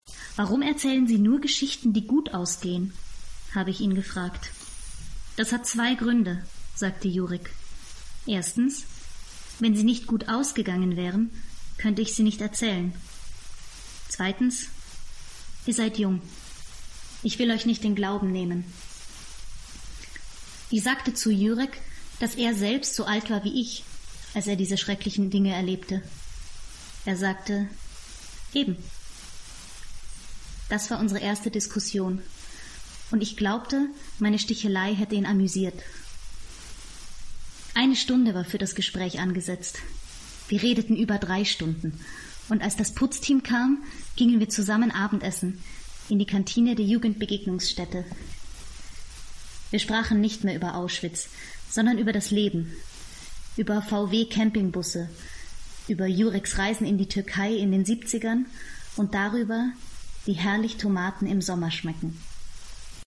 Sprachproben
Sprecherin, Werbesprecherin